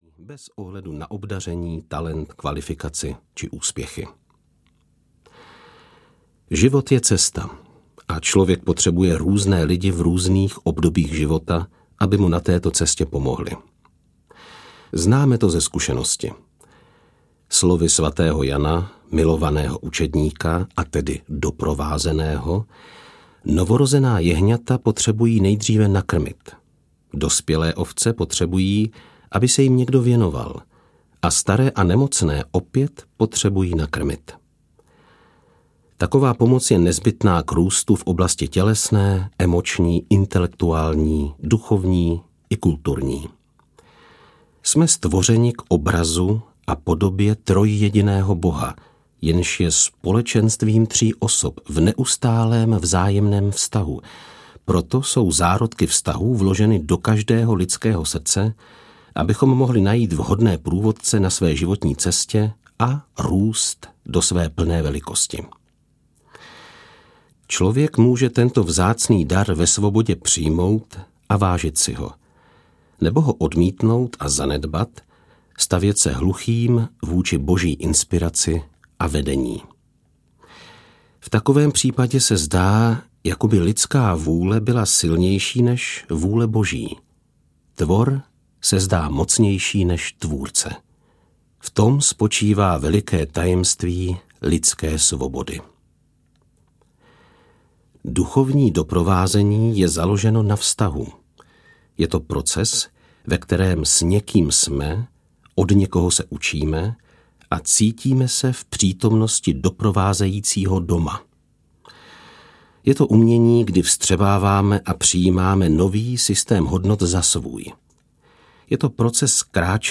Duchovní doprovázení audiokniha
Ukázka z knihy